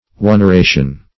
Oneration \On`er*a"tion\, n. The act of loading.